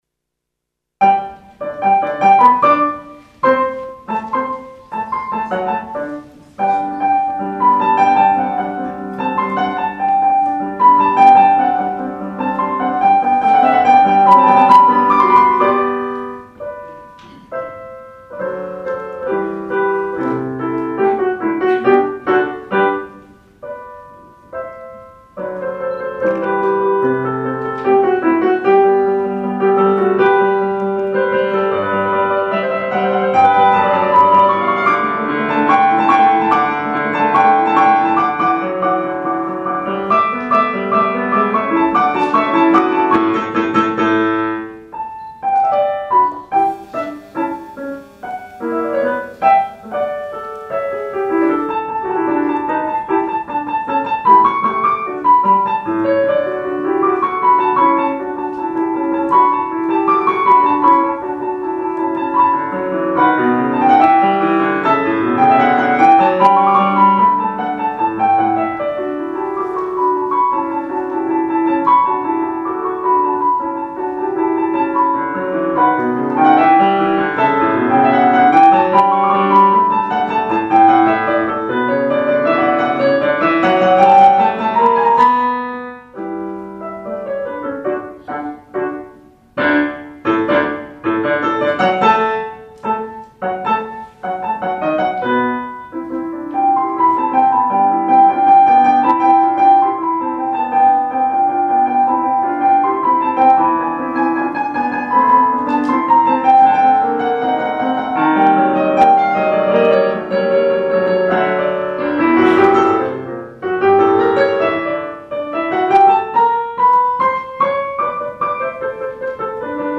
invitate la ediţia a II-a a Festivalului “W.A.Mozart “de la Braşov în cadrul căruia au susţinut recitalul “Pianul cu poeme”.